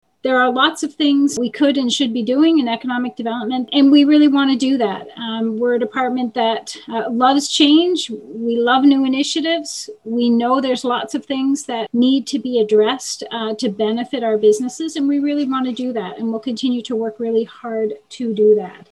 Belleville's Economic and Destination Development Committee meets virtually, on February 25, 2021.